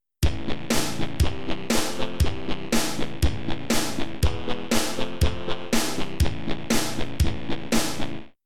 Venturing into Electronic Music
I’m aiming for something simple, which could probably be described as minimalist techno/dance music.
I decided to try and randomly generate a simple bass riff and a random synthesizer patch to play that riff at the same time.